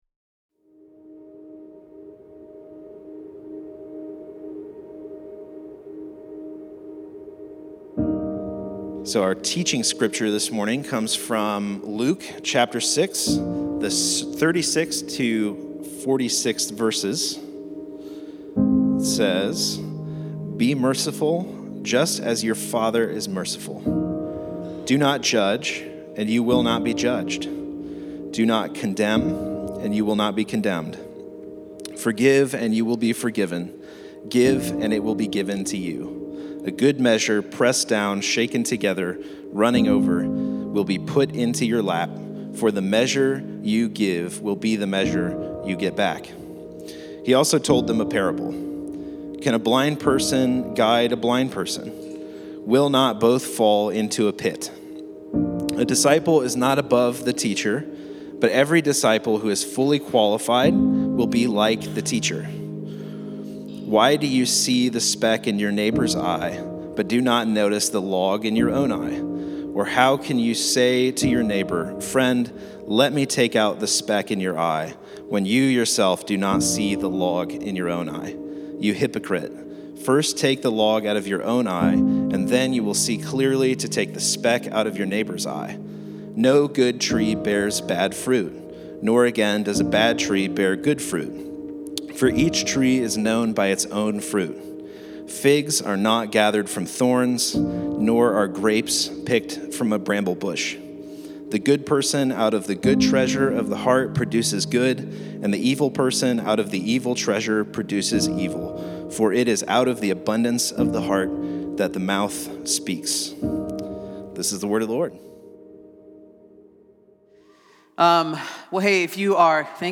Sermons | Central Vineyard Church